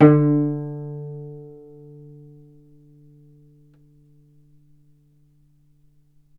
vc_pz-D3-ff.AIF